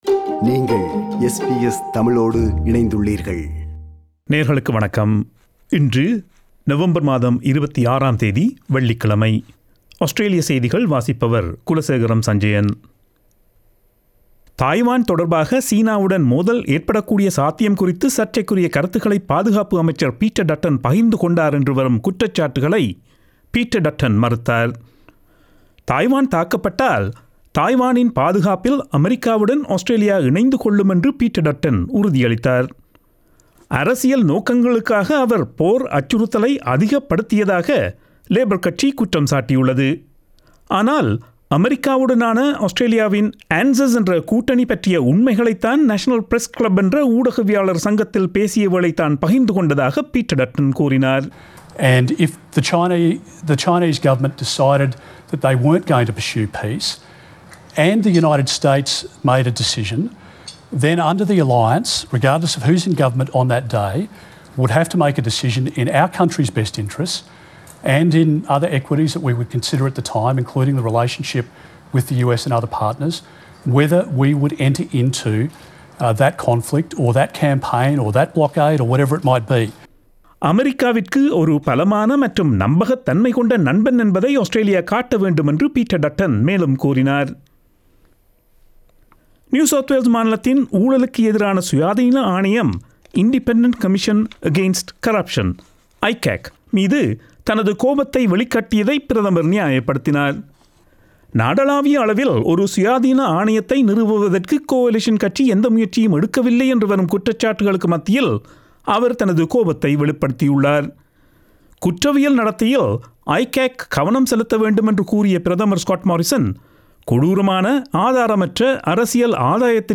Australian news bulletin for Friday 26 Nov 2021.